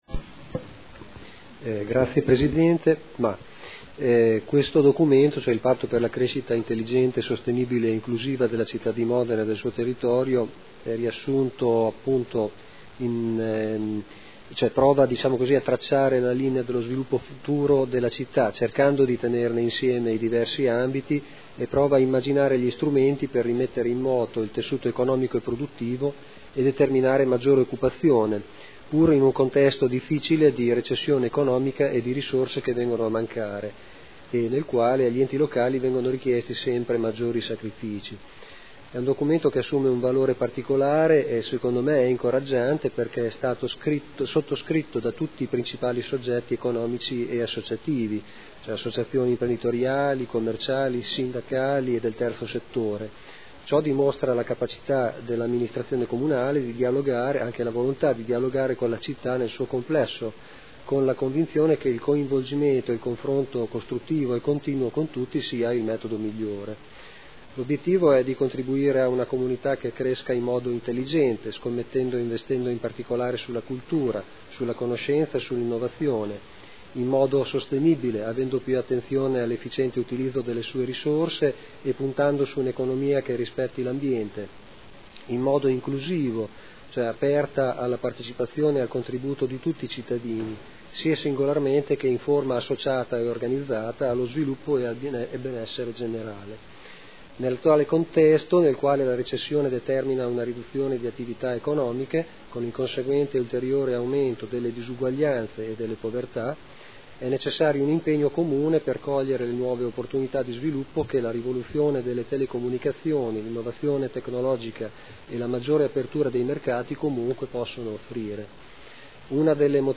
Seduta del 20/11/2014. Dibattito su Ordini del Giorno e Mozione aventi per oggetto "Patto per Modena"